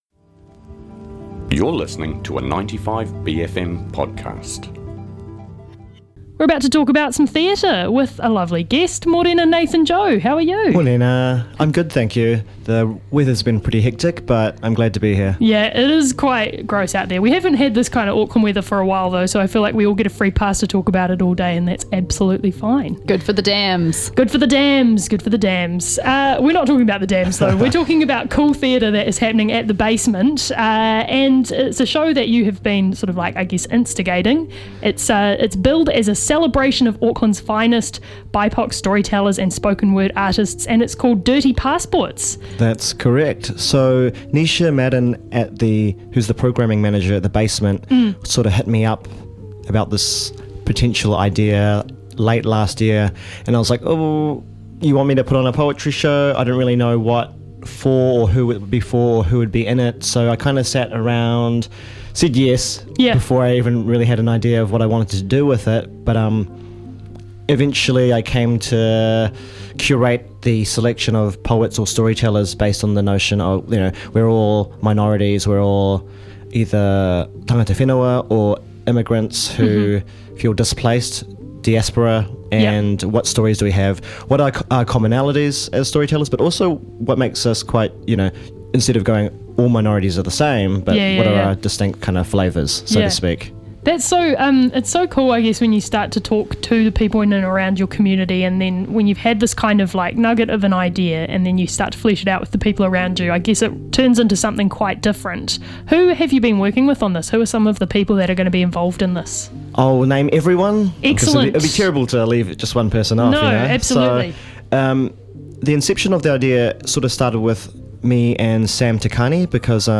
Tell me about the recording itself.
pops into studio to talk about about DIRTY PASSPORTS, a celebration of Tāmaki Makaurau's finest BIPOC storytellers and spoken word artists, on now at Basement Theatre.